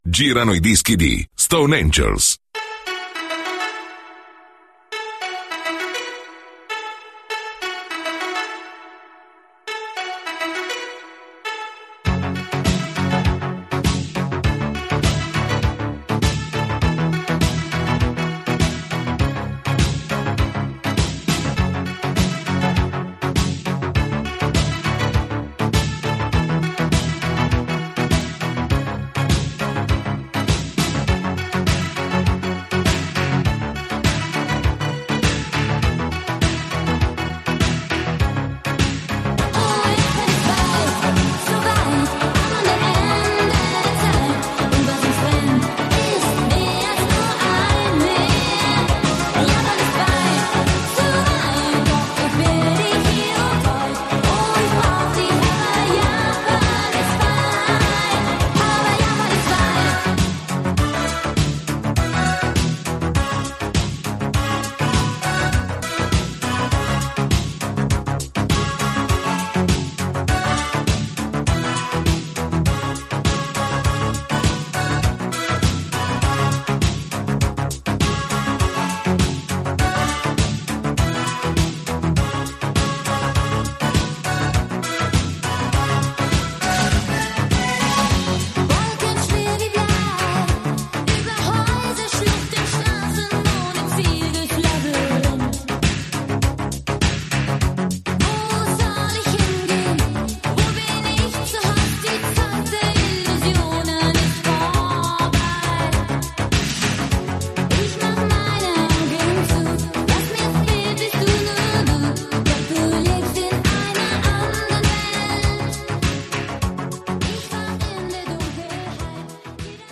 MEGAMIX (frag)